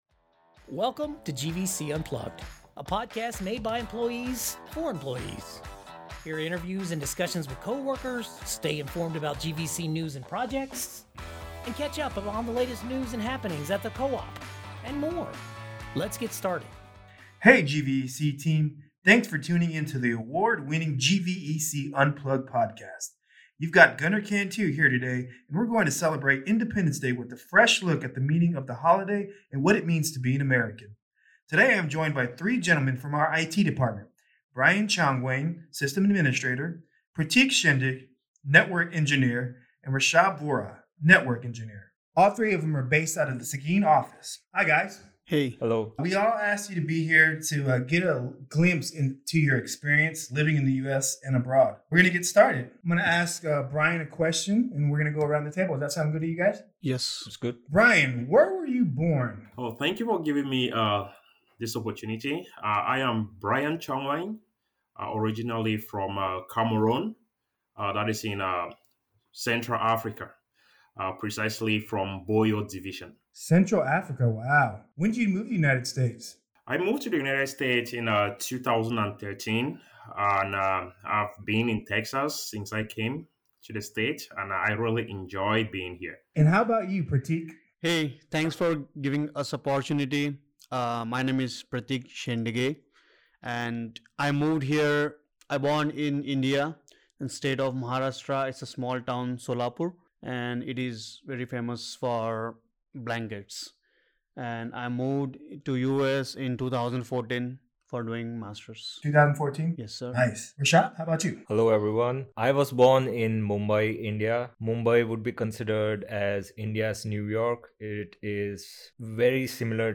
The latest episode of the Award Winning GVEC Unplugged Podcast celebrates Independence day with a heart-warming interview.